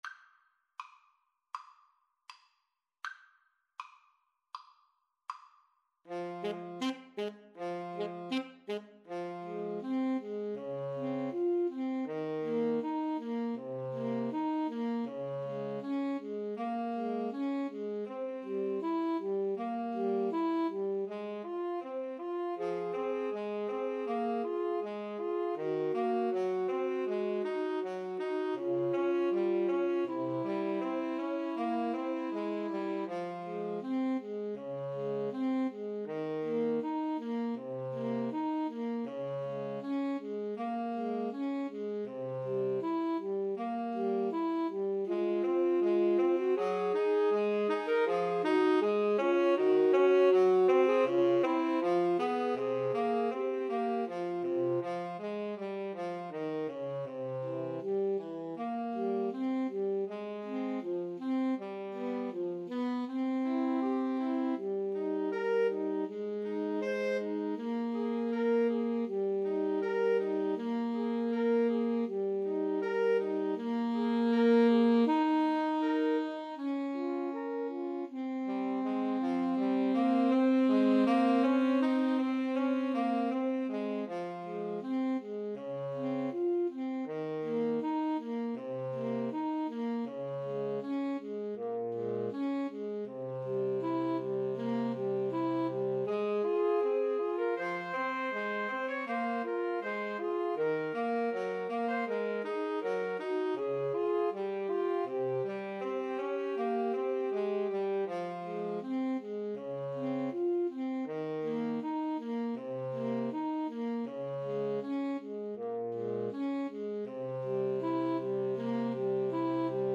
Alto Saxophone 1Alto Saxophone 2Tenor Saxophone
Andante
Classical (View more Classical 2-Altos-Tenor-Sax Music)